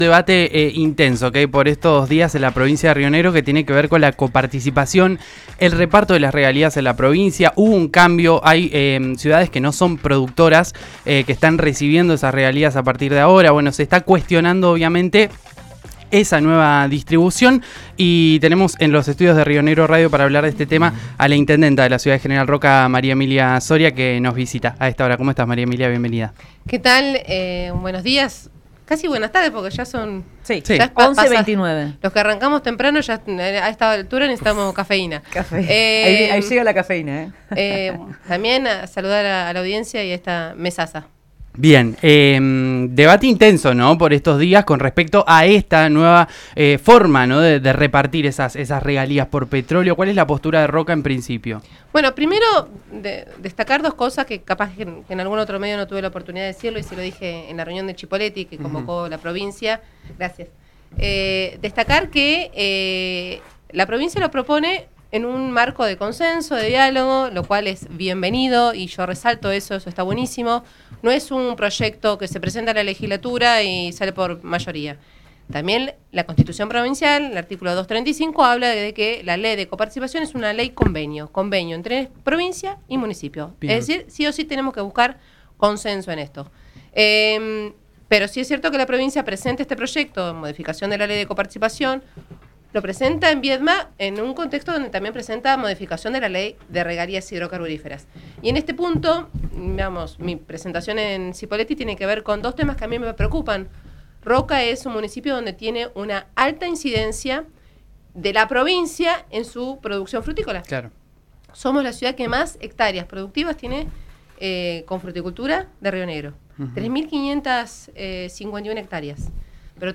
En diálogo con RÍO NEGRO Radio, la intendenta de Roca, María Emilia Soria cuestionó el esquema de reparto de las regalías hidrocarburíferas que impulsa la provincia de Río Negro, al advertir que la ciudad podría perder hasta un 50% de los ingresos y quedar en desventaja frente a los municipios con menor población y actividad productiva.